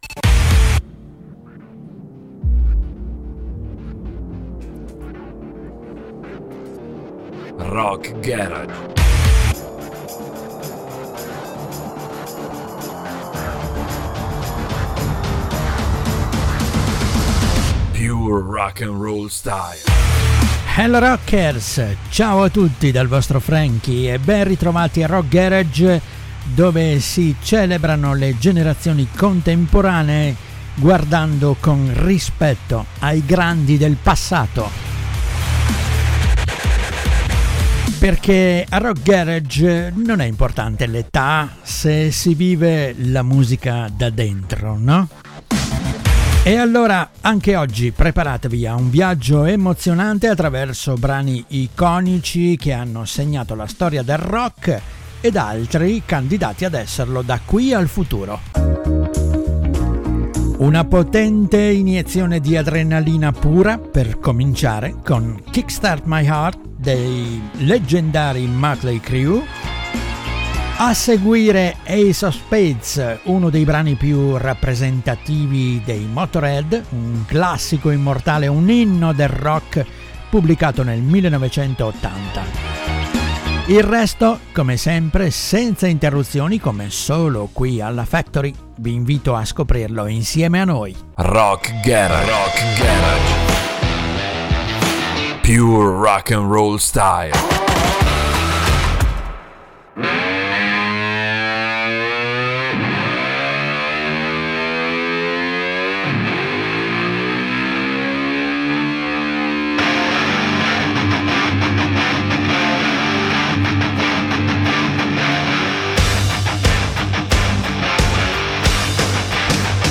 Una compilation senza interruzioni pubblicitarie con: